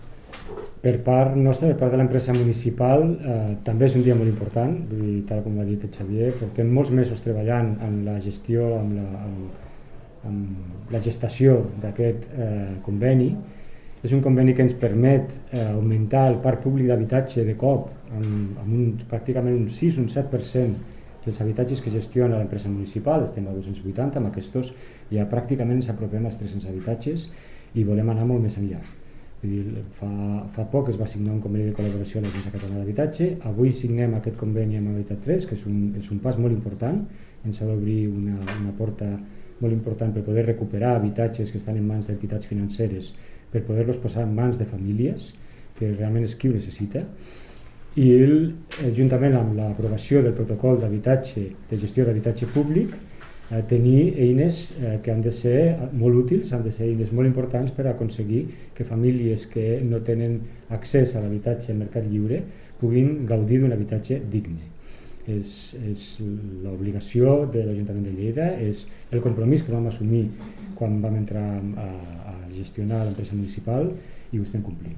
tall-de-veu-del-tinent-dalcalde-sergi-talamonte-sobre-el-nou-conveni-amb-habitat3